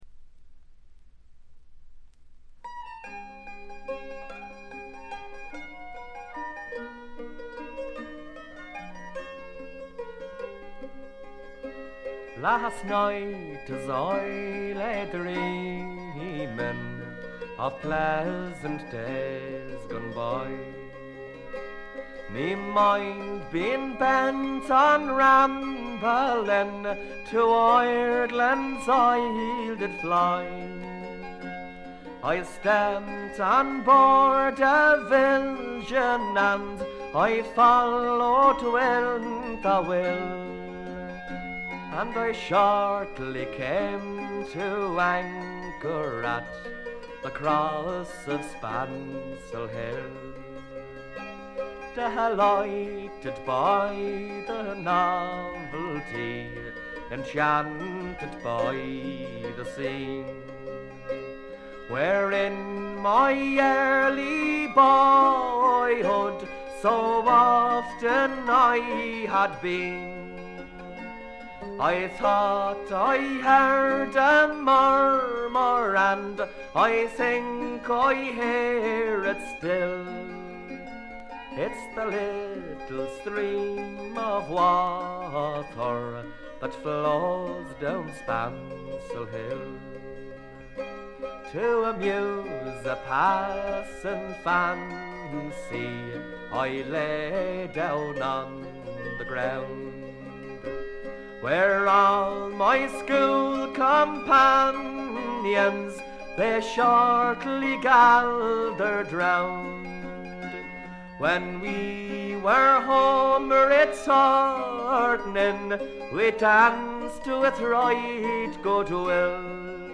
中身は哀切なヴォイスが切々と迫る名盤。
試聴曲は現品からの取り込み音源です。